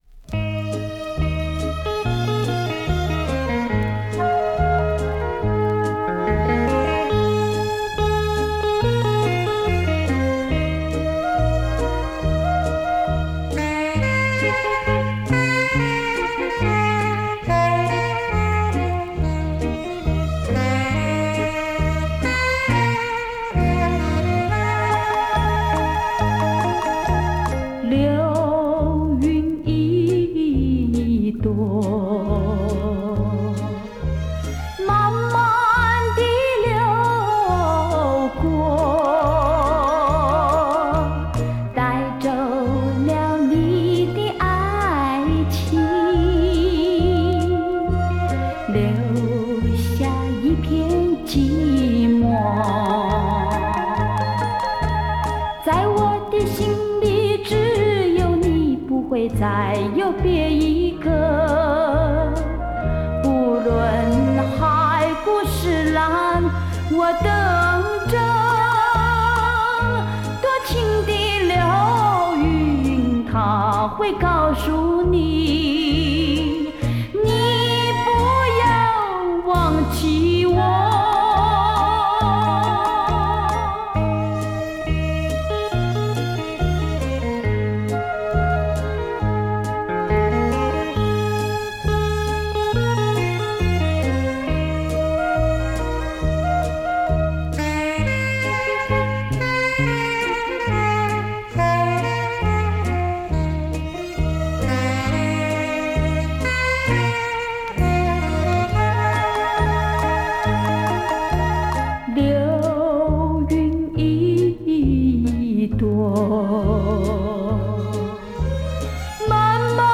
黑胶唱片